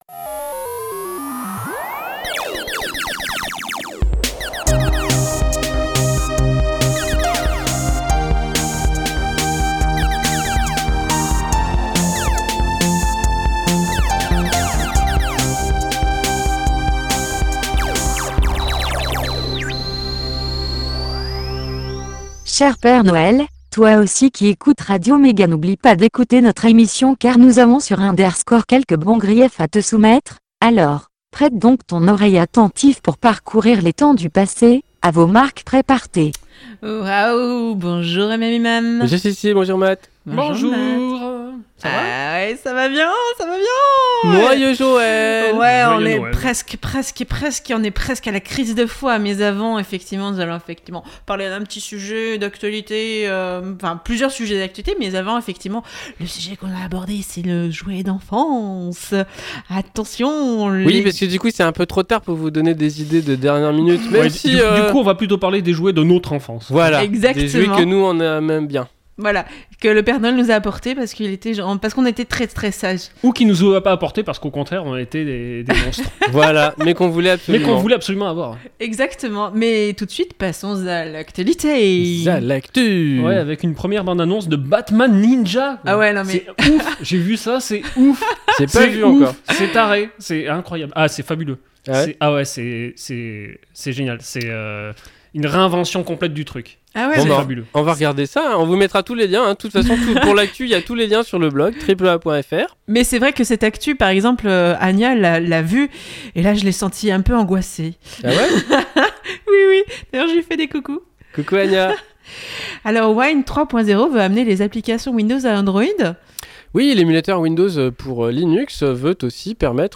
Des jouets pour Noël, c'était comment avant ? De l'actu, une pause chiptune, un sujet, l'agenda, et astrologeek !